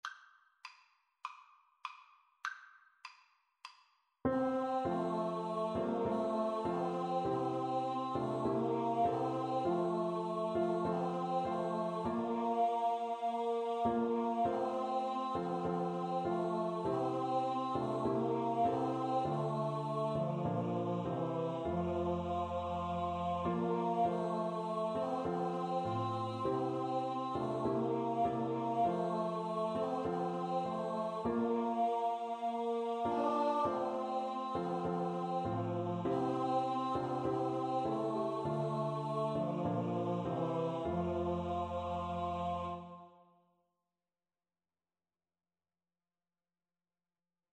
Free Sheet music for Choir (SATB)
Scottish